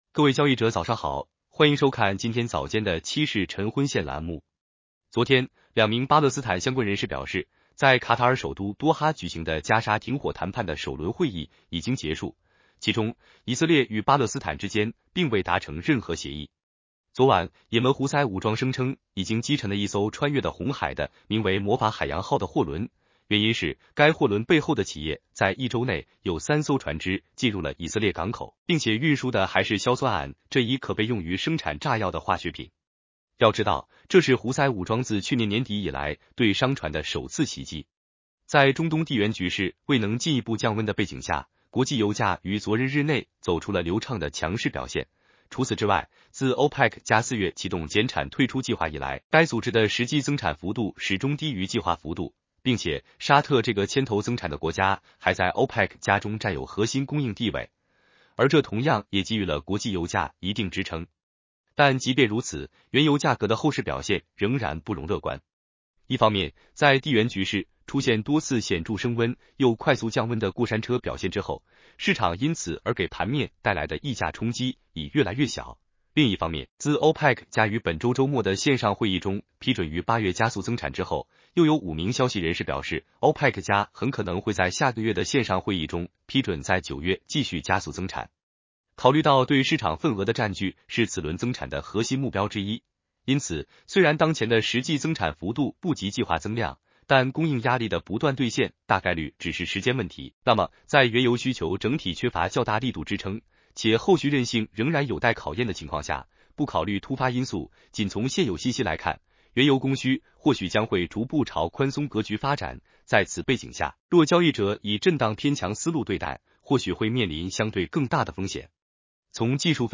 男生普通话版 下载mp3